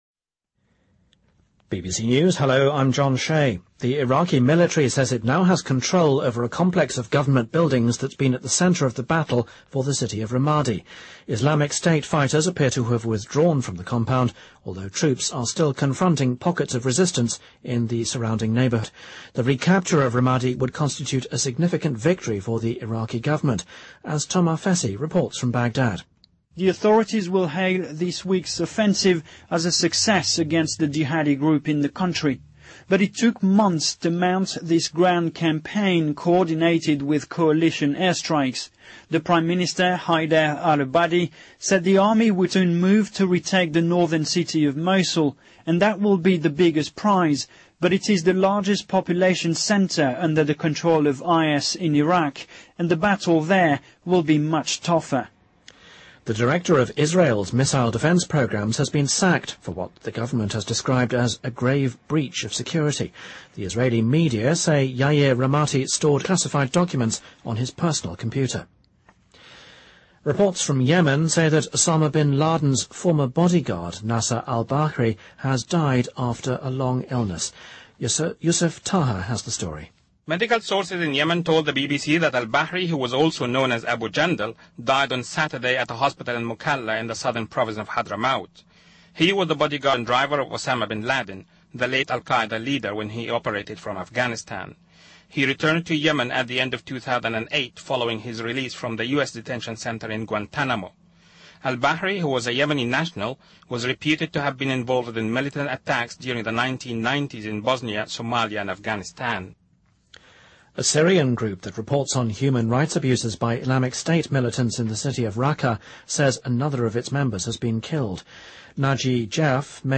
BBC news,伊拉克收复拉马迪政府大楼